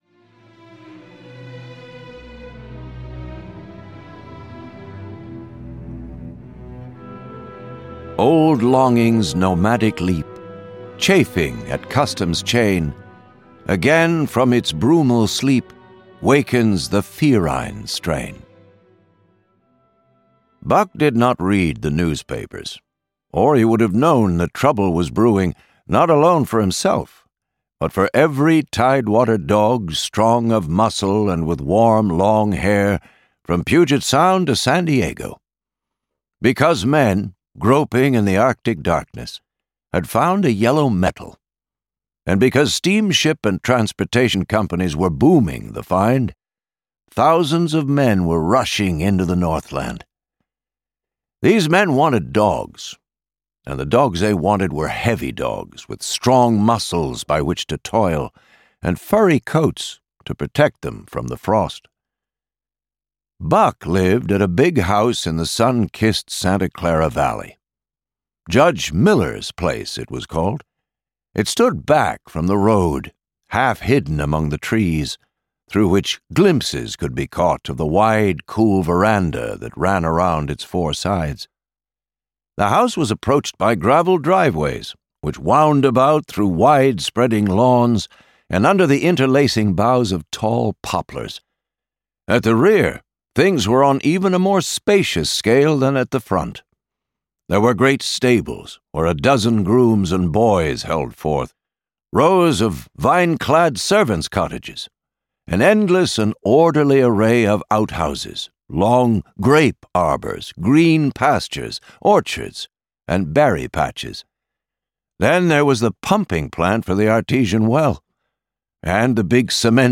The Call of the Wild (EN) audiokniha
Ukázka z knihy